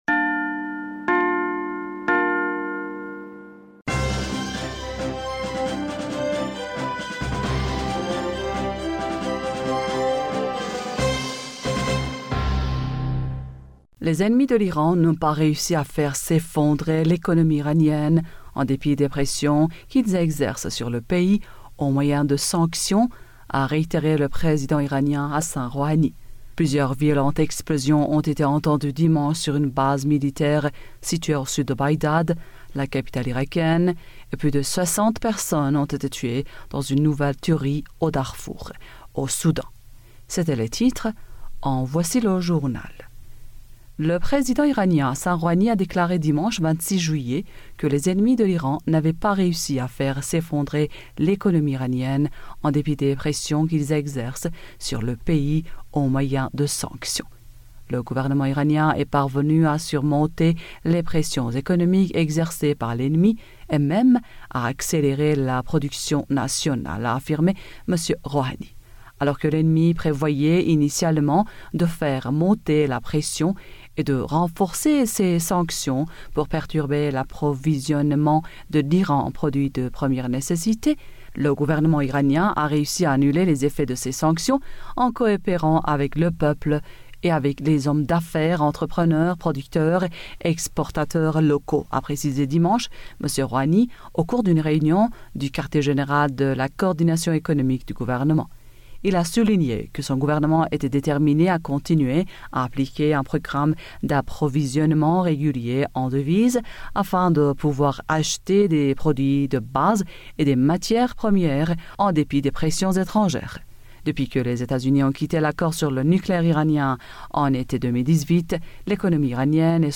Bulletin d'information du 27 Juillet 2020